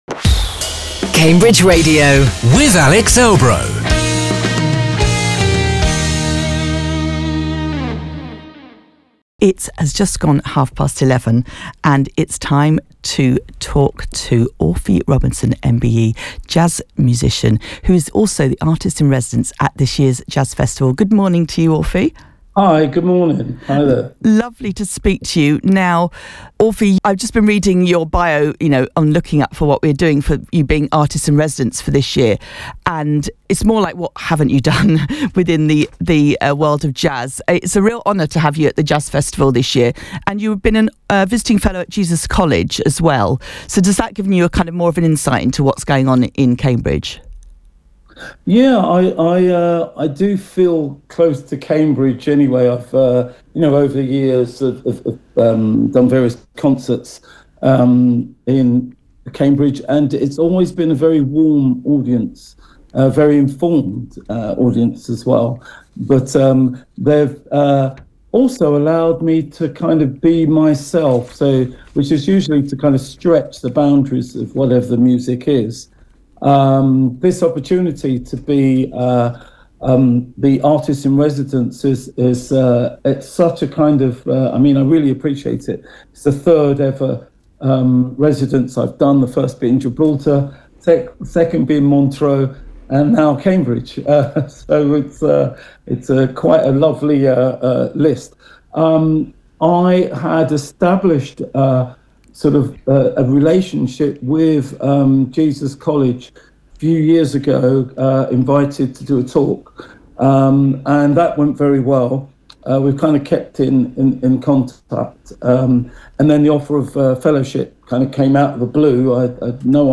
Jazz musician Orphy Robinson MBE, artist in residence for this year’s Cambridge Jazz Festival.